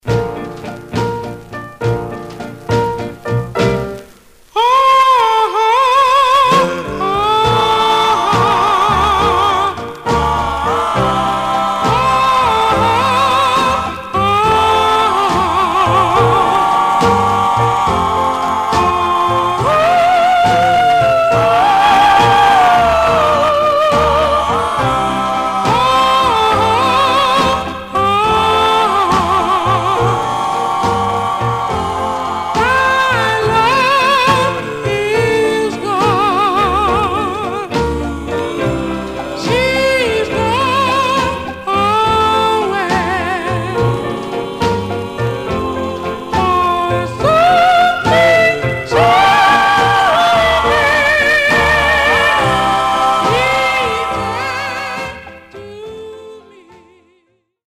Some surface noise/wear
Mono
Male Black Groups